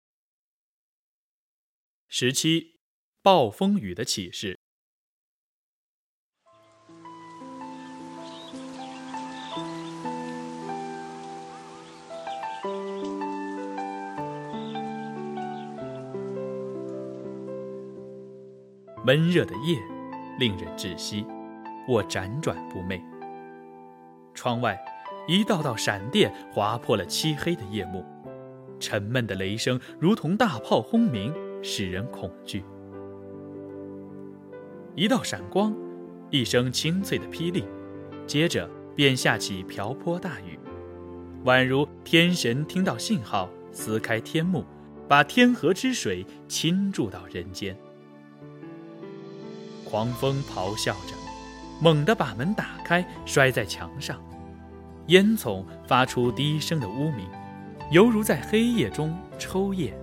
六年级语文下册朗读 暴风雨的启示（西师大版）_21世纪教育网-二一教育